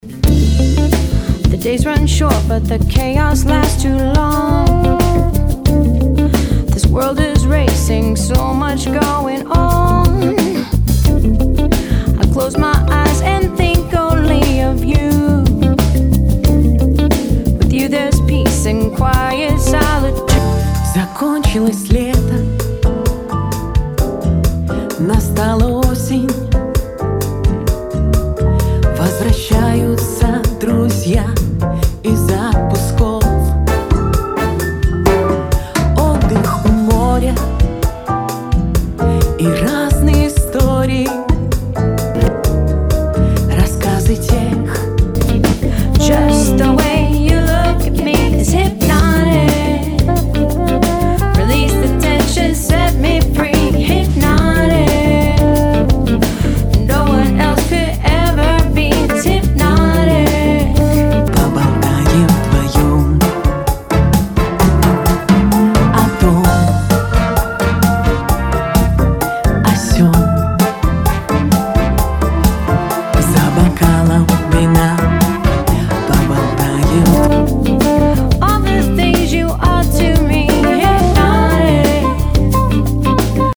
Послушайте сравнение реф.и моя аранж.
Здесь миксы сравнения и мой последний вариант.